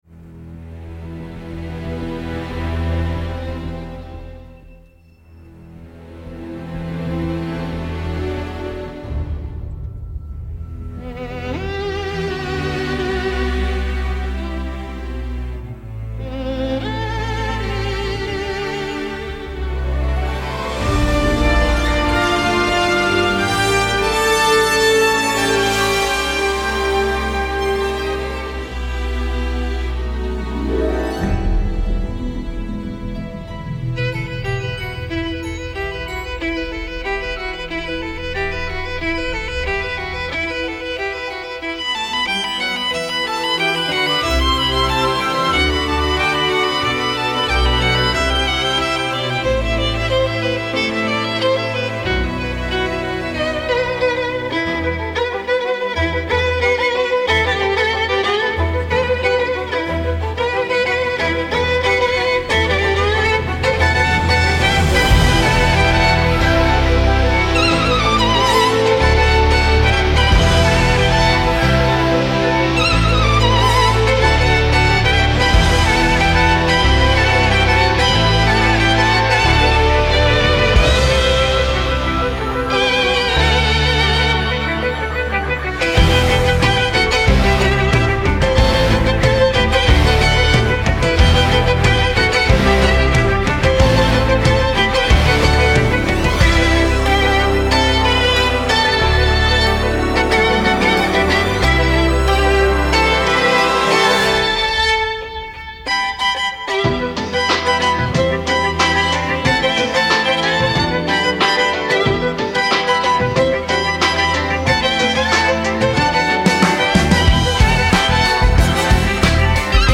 لایتموسیقی
نوع آهنگ: لایت]